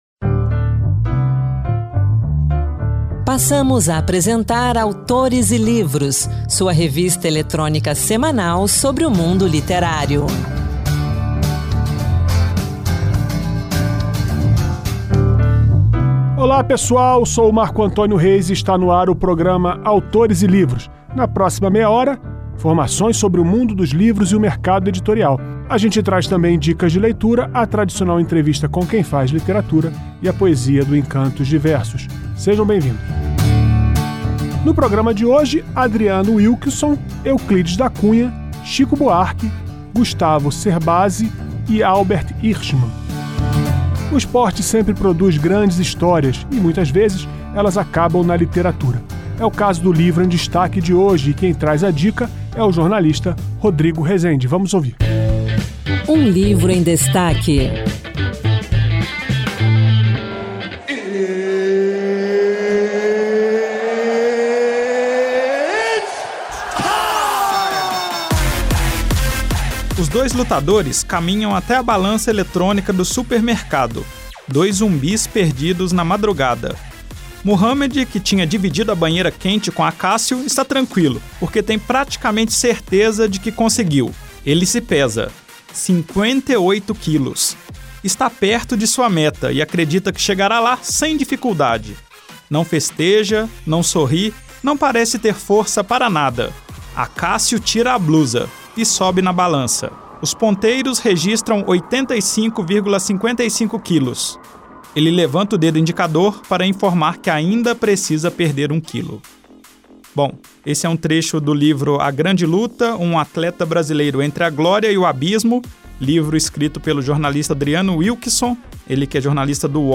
Revista literária com entrevistas com autores, poesias, dicas de livros e também notícias sobre o mundo da literatura e as últimas publicações do Senado Federal